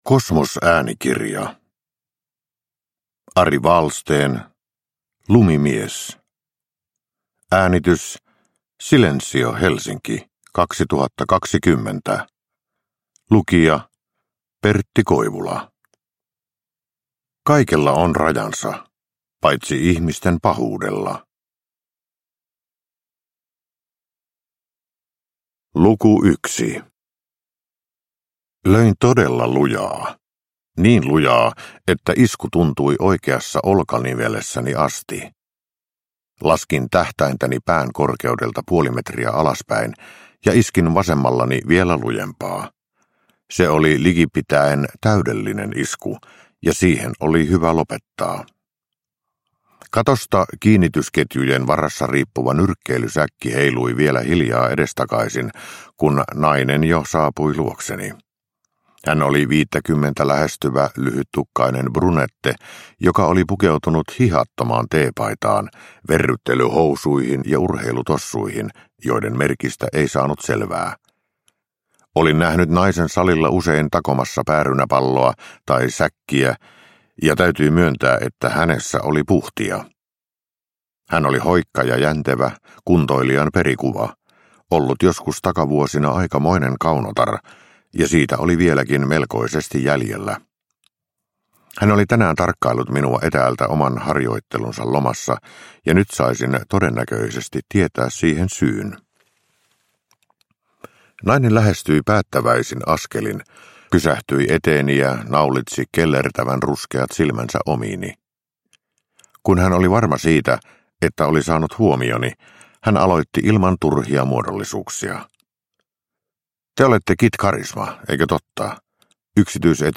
Lumimies – Ljudbok – Laddas ner